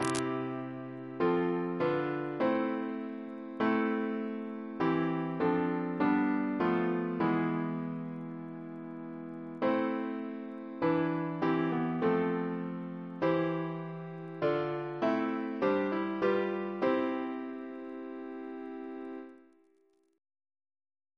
Double chant in C Composer